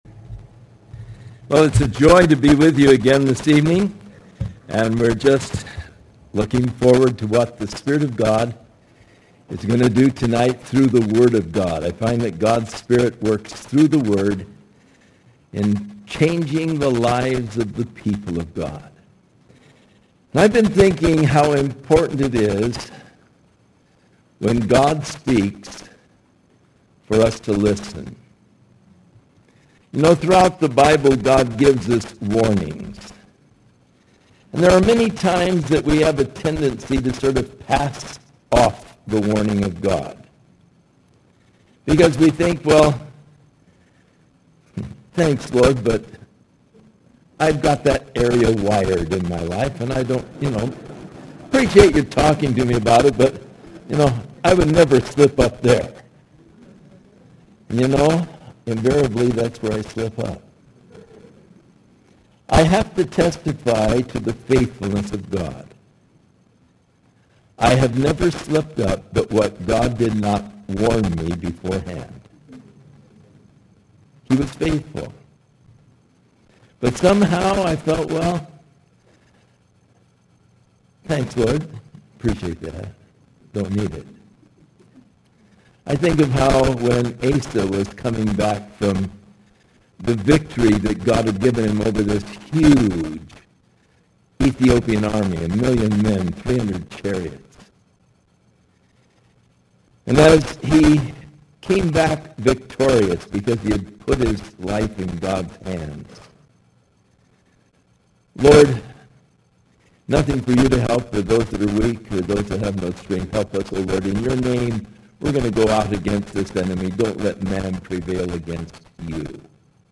Session 4 Speaker: Chuck Smith Series: 2002 DSPC Conference: Pastors & Leaders Date: January 16, 2002 Home » Sermons » Session 4 Share Facebook Twitter LinkedIn Email Topics: Session 4 « Session 3 Session 5 »